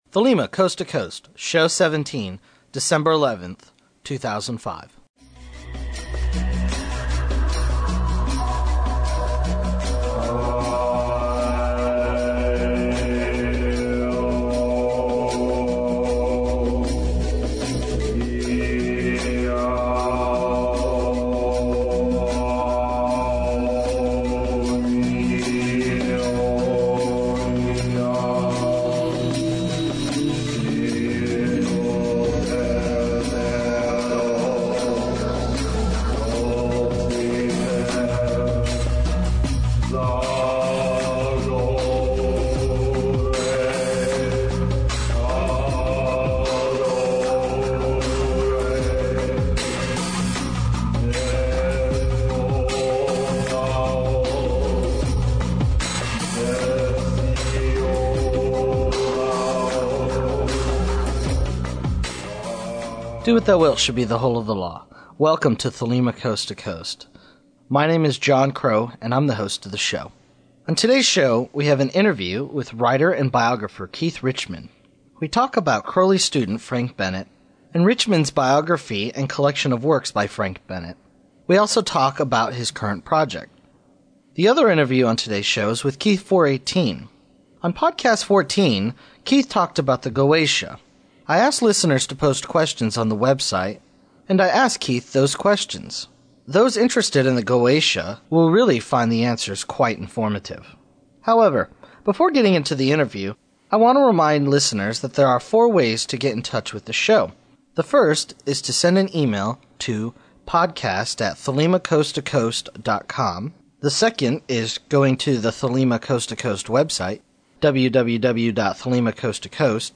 Opening music
Closing music